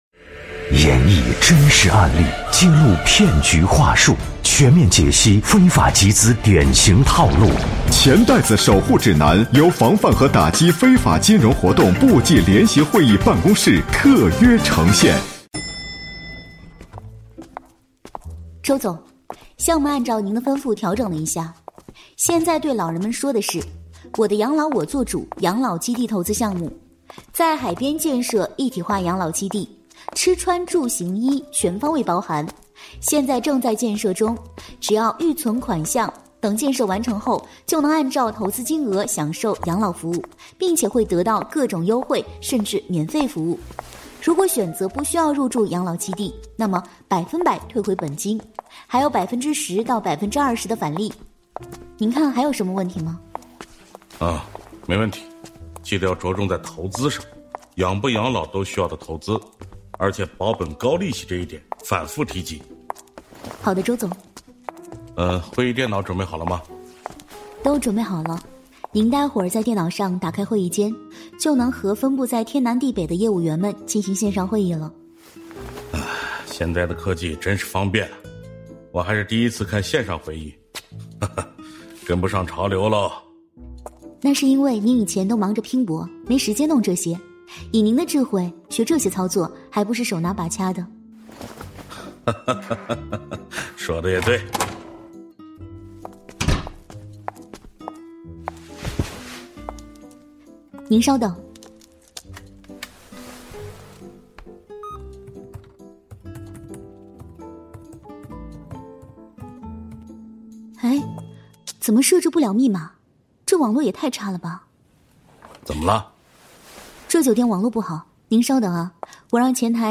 《钱袋子守护指南》栏目丨第十二集 我的养老我做主 来源：防范和打击非法金融活动部际联席会议办公室和云听联合呈现 时间：2025-01-22 19:00 微信 微博 QQ空间 《钱袋子守护指南》是经济之声联合防范和打击非法金融活动部际联席会议办公室特别策划推出的一档防范非法集资科普栏目。选取真实案例，透过典型情节演示非法集资对个人和社会带来的危害，更生动地传递“反非”的理念，增强社会的风险意识和预防能力。